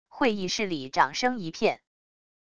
会议室里掌声一片wav音频